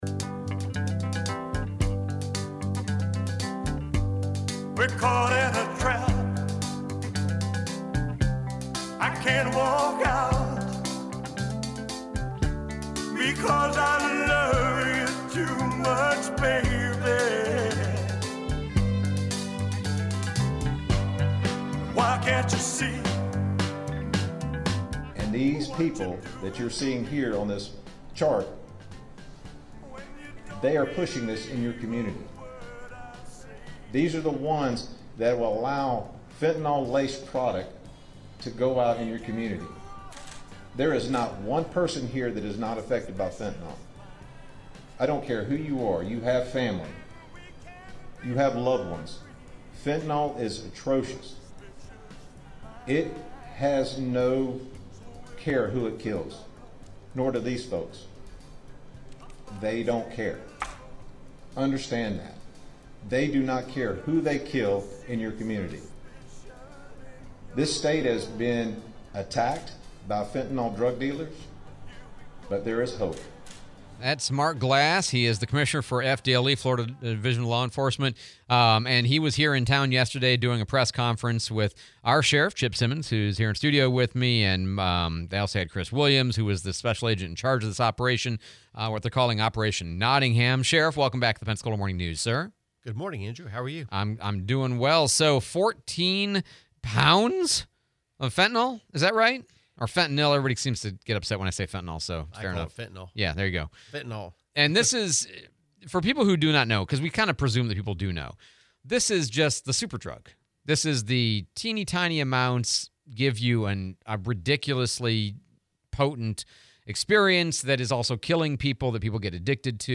11/25/25 Sheriff Simmons interview